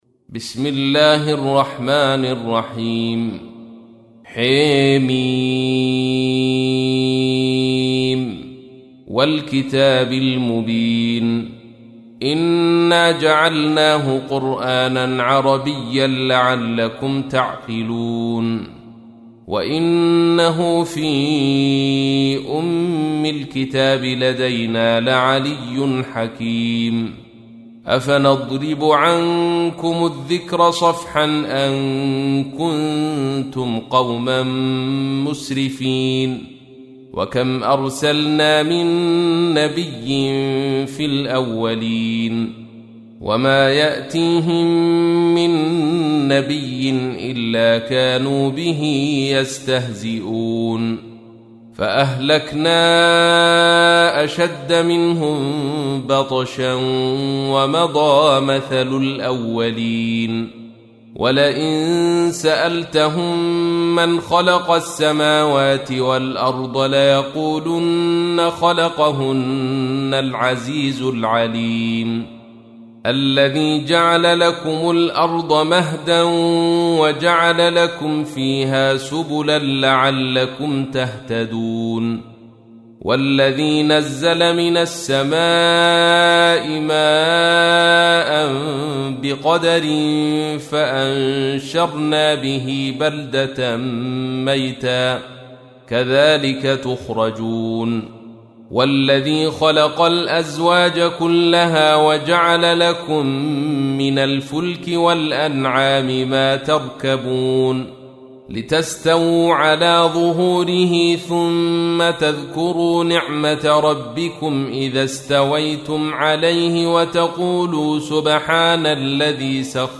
تحميل : 43. سورة الزخرف / القارئ عبد الرشيد صوفي / القرآن الكريم / موقع يا حسين